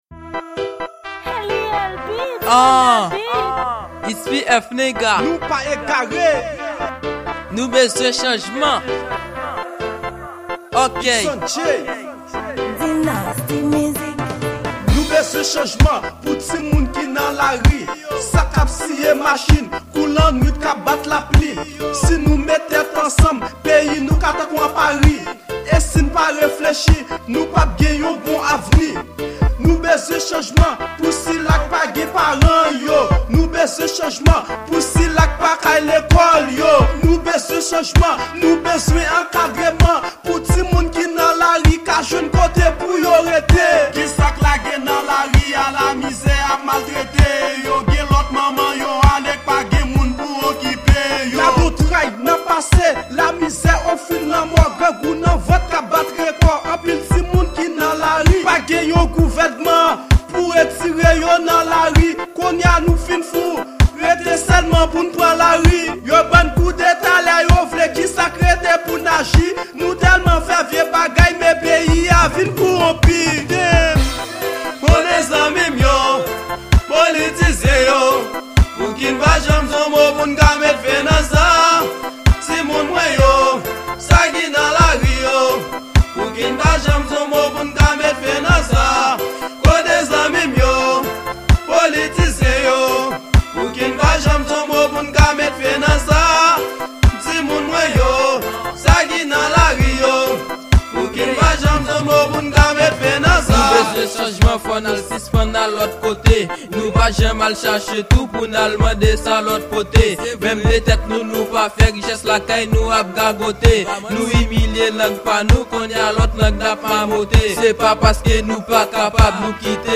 Genre; Rap